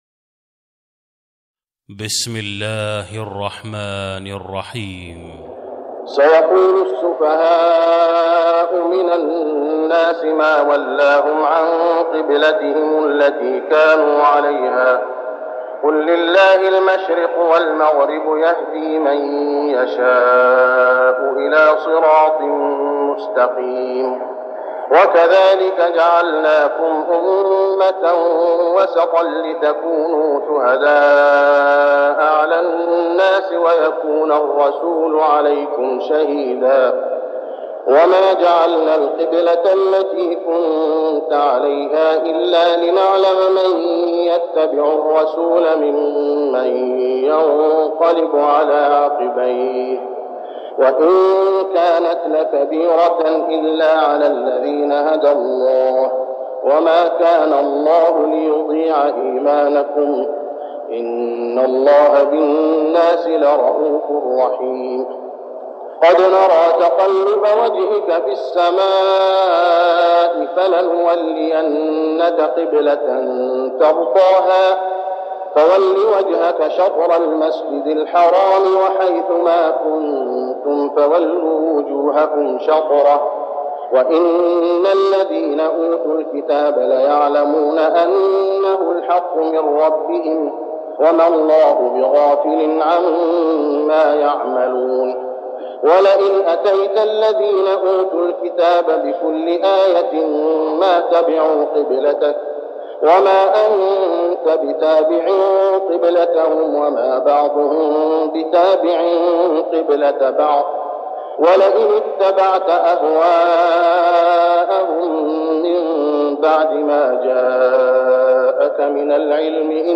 صلاة التراويح ليلة 3-9-1410هـ سورة البقرة 142-203 | Tarawih prayer Surah Al-Baqarah > تراويح الحرم المكي عام 1410 🕋 > التراويح - تلاوات الحرمين